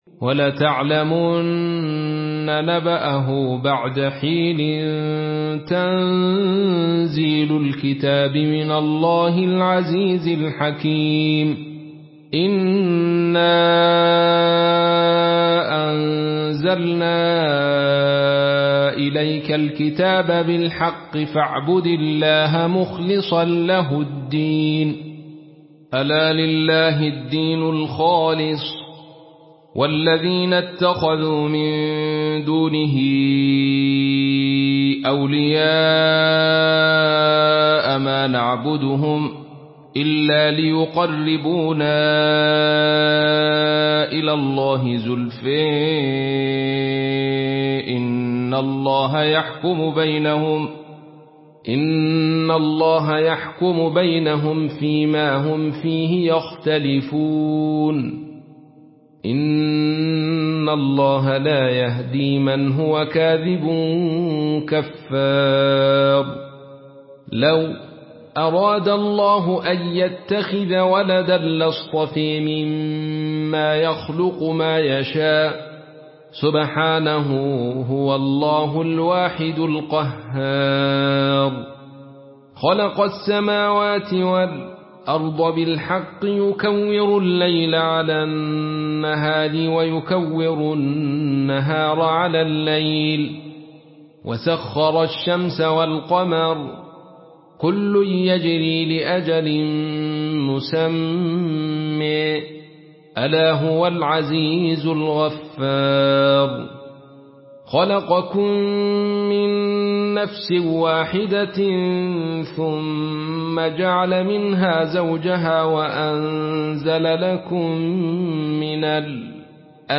مرتل خلف عن حمزة